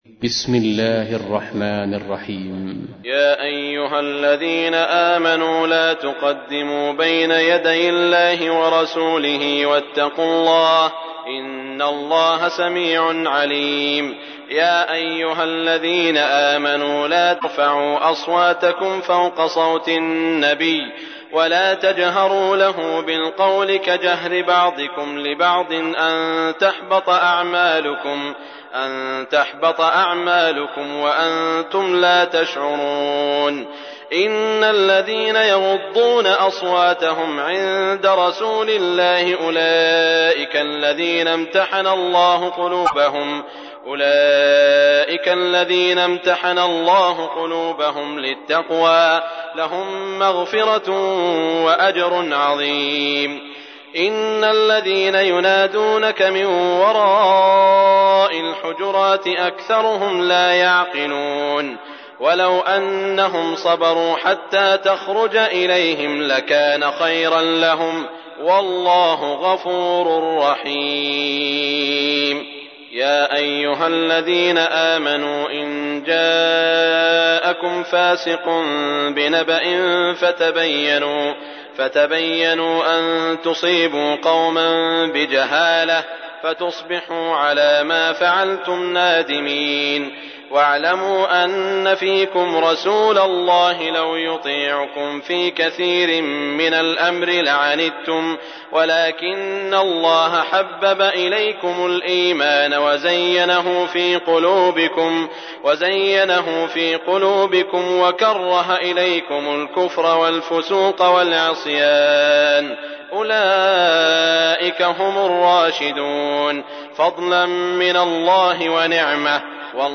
تحميل : 49. سورة الحجرات / القارئ سعود الشريم / القرآن الكريم / موقع يا حسين